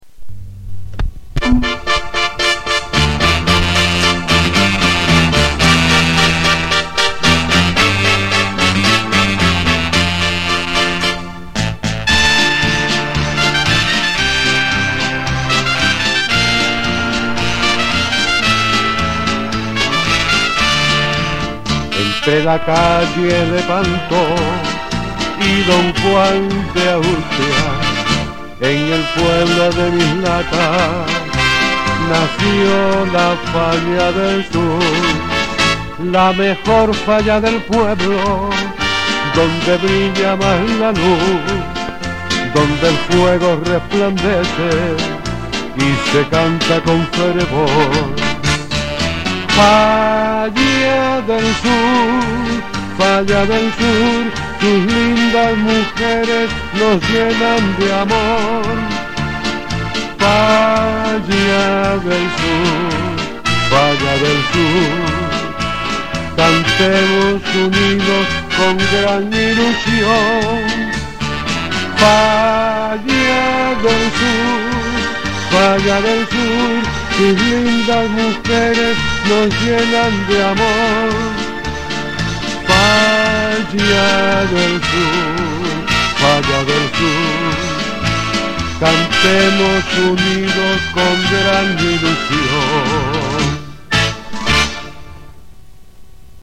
Himno-Falla-del-Sur-Stereo.mp3